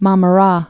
(mä-mə-rā)